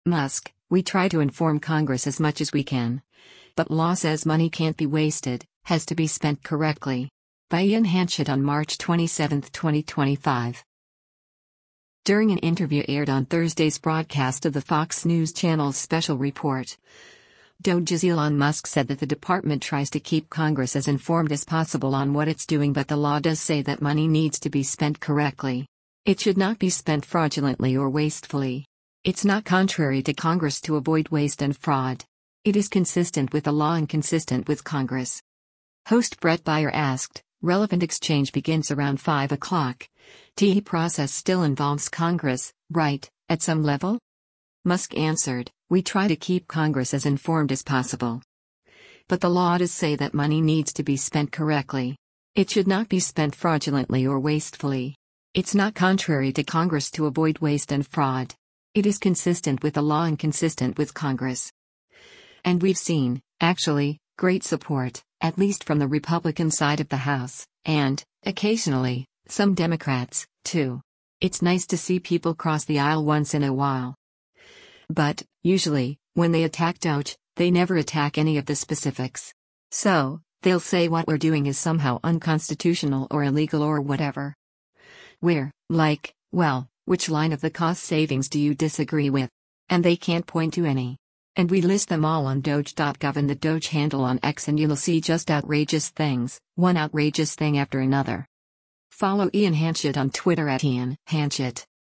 During an interview aired on Thursday’s broadcast of the Fox News Channel’s “Special Report,” DOGE’s Elon Musk said that the department tries to keep Congress “as informed as possible” on what it’s doing “But the law does say that money needs to be spent correctly. It should not be spent fraudulently or wastefully. It’s not contrary to Congress to avoid waste and fraud. It is consistent with the law and consistent with Congress.”